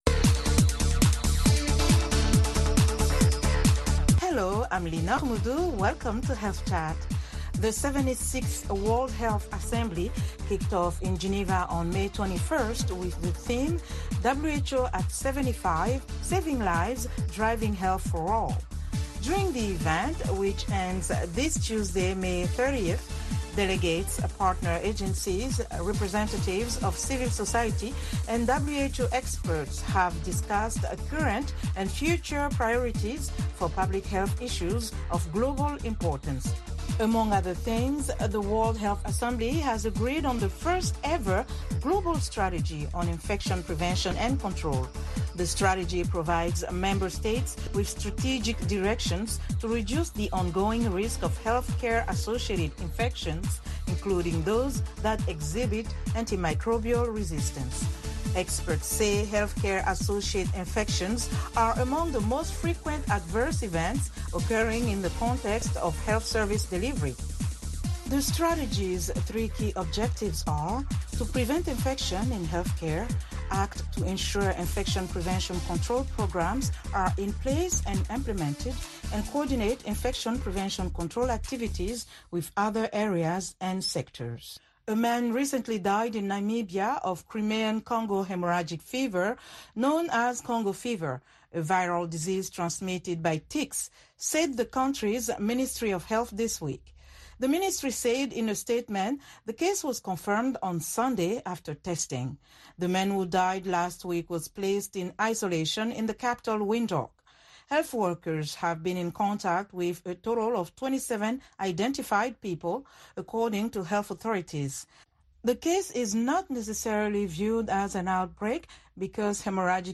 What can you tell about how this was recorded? Health Chat is a live call-in program that addresses health issues of interest to Africa. The show puts listeners directly in touch with guest medical professionals. It includes a weekly feature spot, news and comments from listeners.